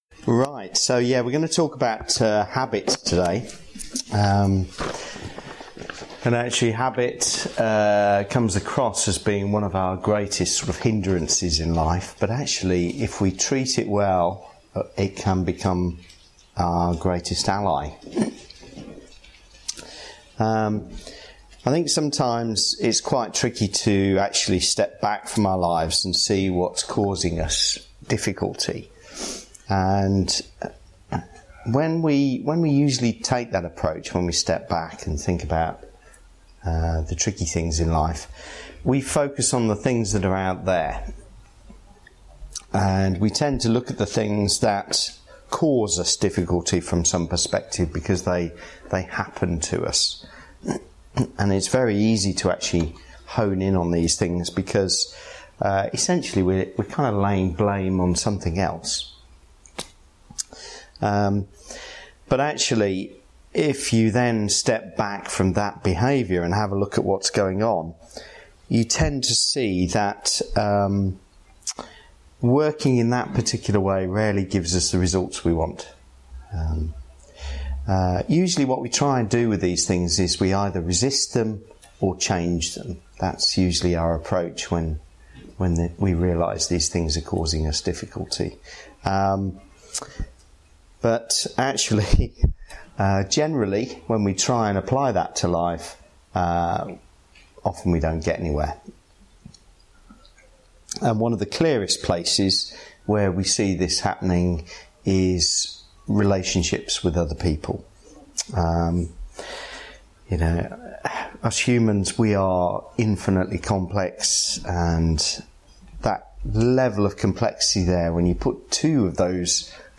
Listen to the talk and meditation here or read the article below.